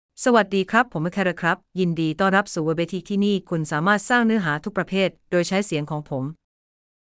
Achara — Female Thai AI voice
Achara is a female AI voice for Thai (Thailand).
Voice sample
Listen to Achara's female Thai voice.
Female
Achara delivers clear pronunciation with authentic Thailand Thai intonation, making your content sound professionally produced.